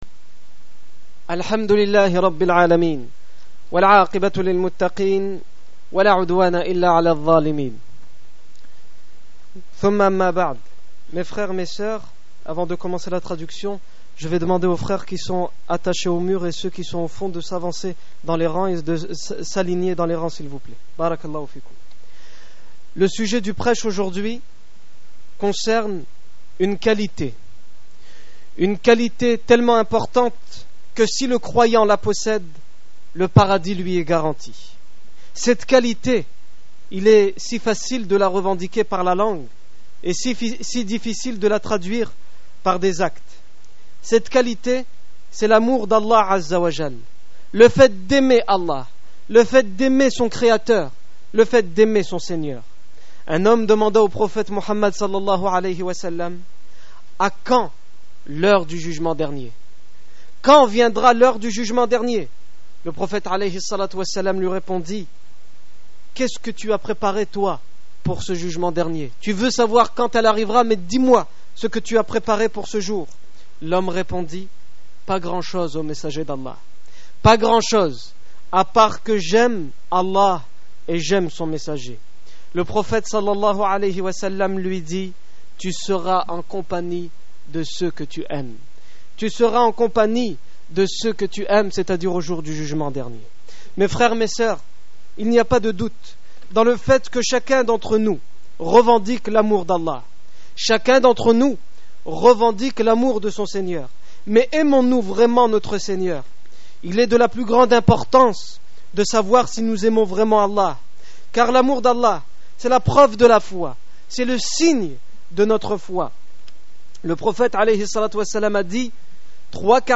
Discours du 27 février 2009
Accueil Discours du vendredi Discours du 27 février 2009 Aimons-nous Allah?